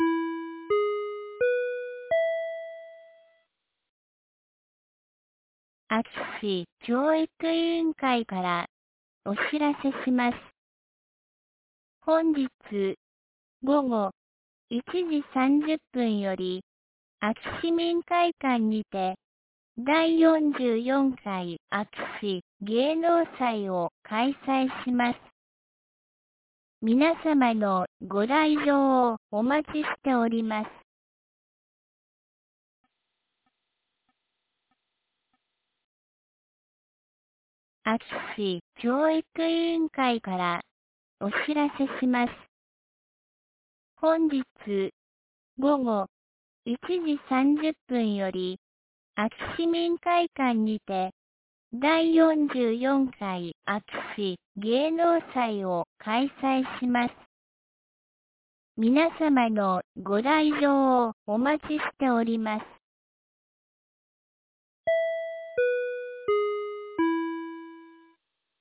2023年10月15日 11時31分に、安芸市より全地区へ放送がありました。